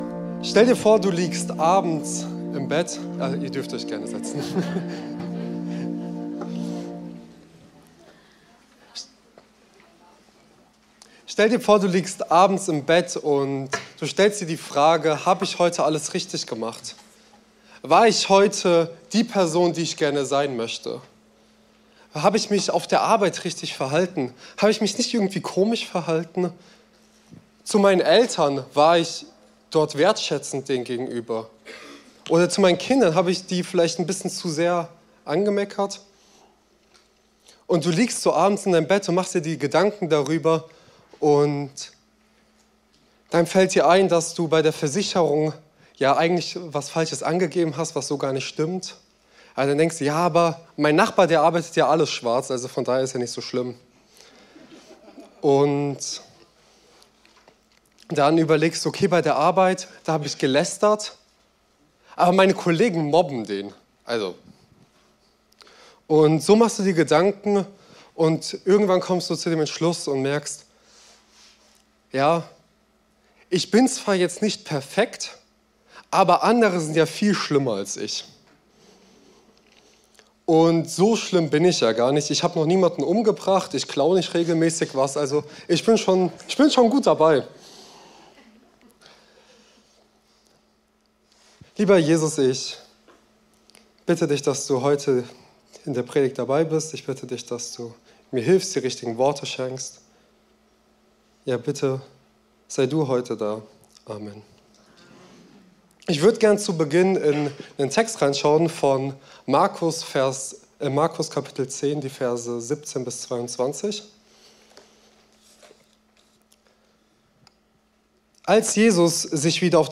Predigten aus der freien Christengemeinde Die Brücke in Bad Kreuznach.